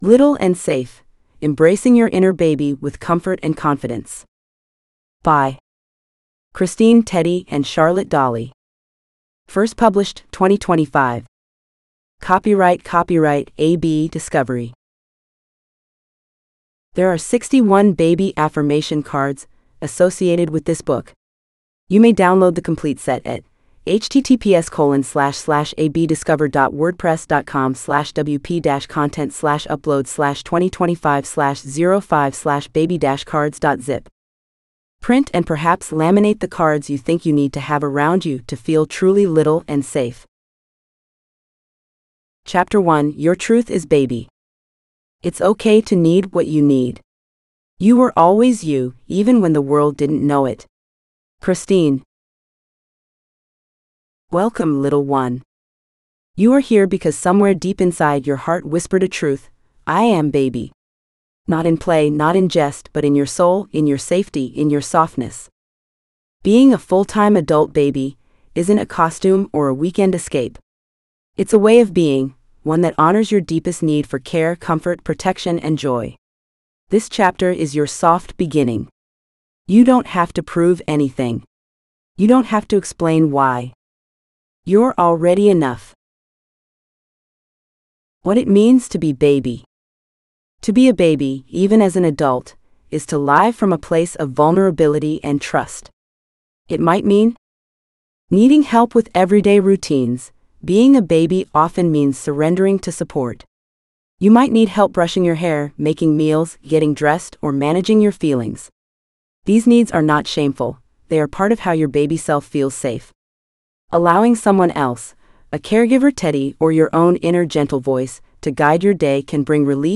Little and Safe (AUDIOBOOK): $U6.50